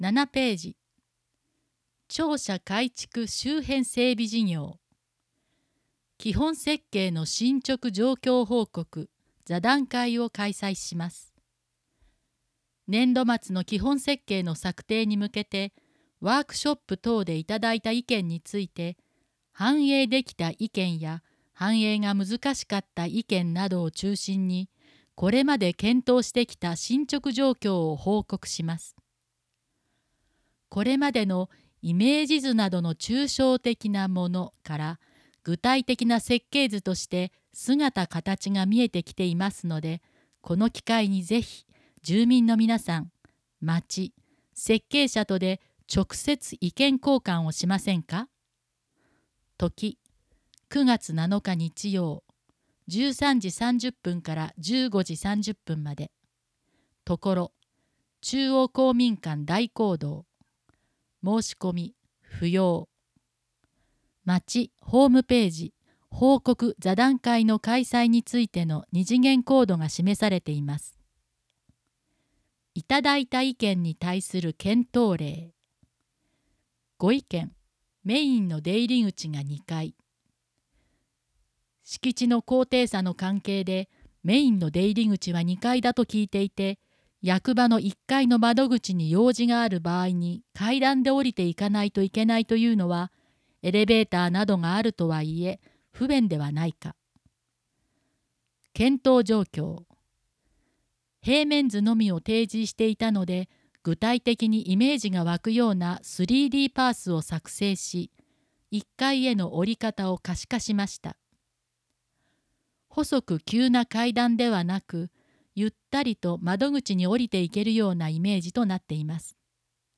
音声データ　軽井沢図書館朗読ボランティア「オオルリ」による朗読